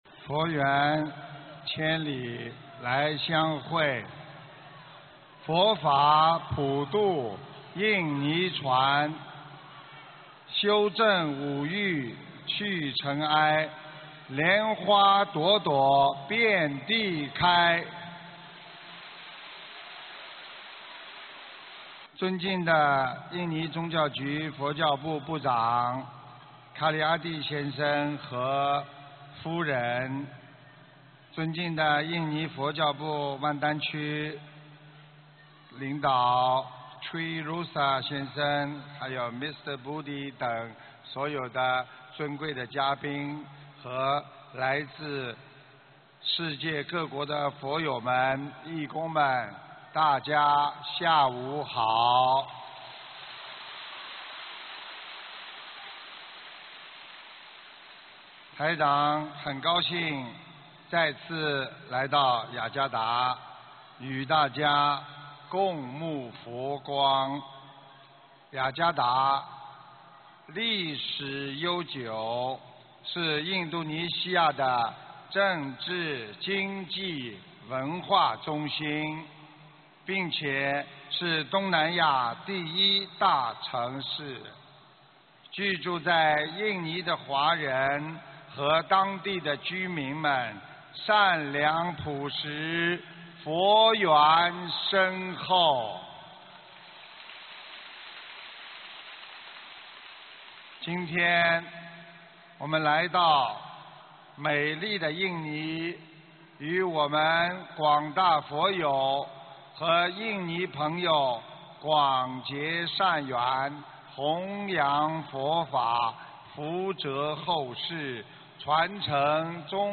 印度尼西亚_雅加达《玄艺综述》解答会开示180311